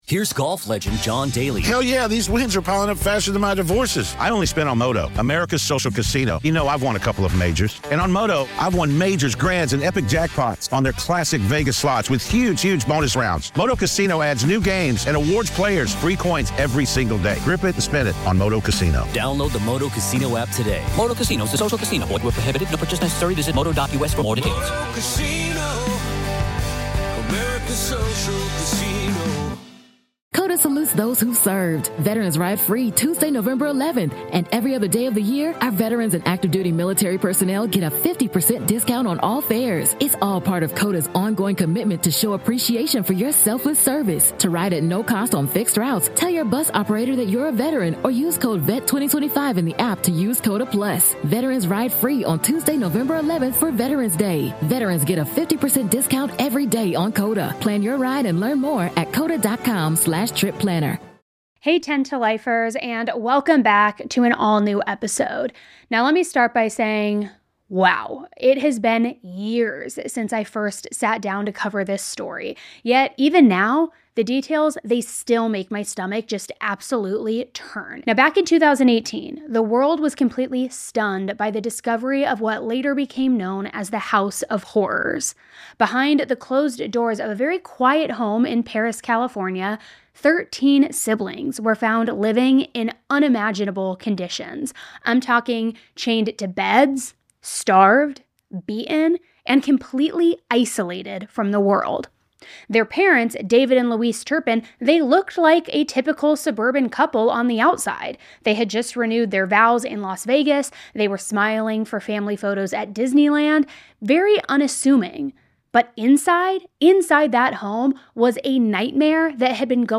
*PSA* Part of this episode includes an older recording of me discussing the case. It sounds extremely fast because that is when I would cover cases in 10 min or less (10 to life). Pacing will not always be like this.